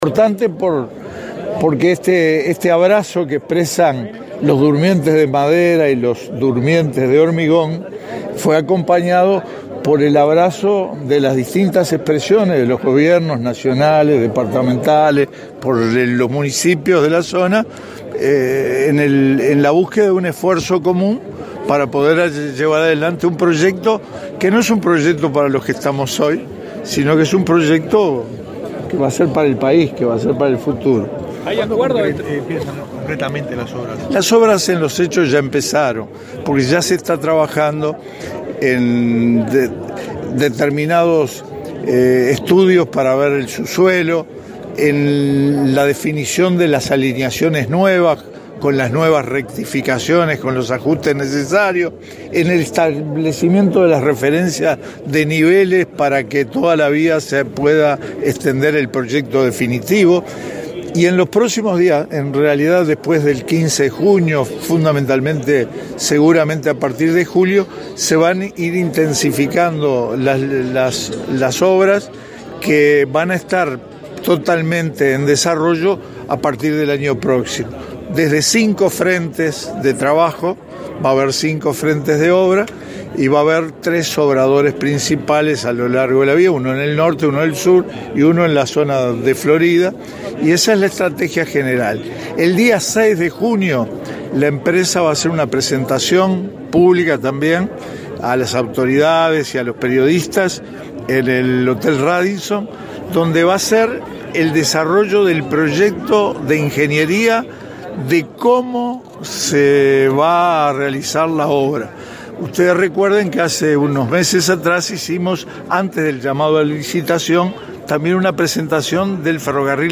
El ministro de Transporte, Víctor Rossi, dijo en Paso de los Toros que el proyecto del Ferrocarril Central ya comenzó y que a partir de julio se intensificarán en cinco frentes de obras.